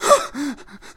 Сильный испуг молодого мужчины
silnij_ispug_molodogo_muzhchini_1x6.mp3